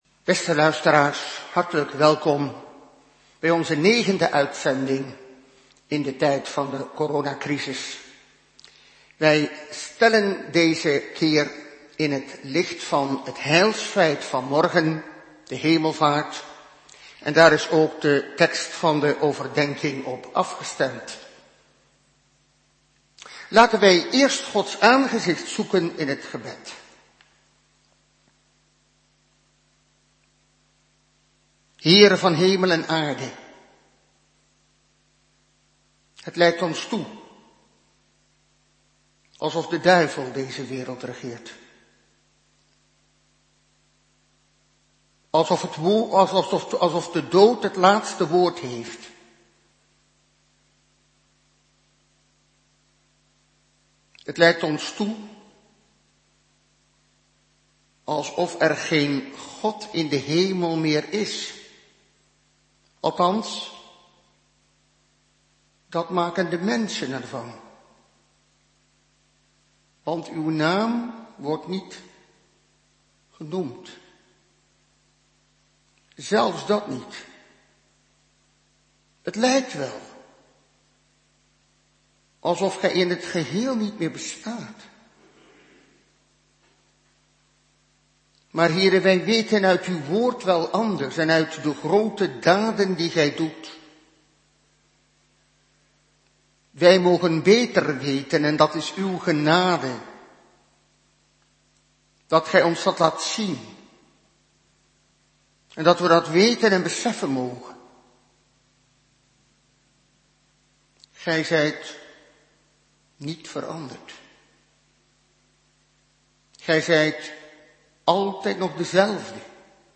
Openbaring 6:1-8 (meditatie)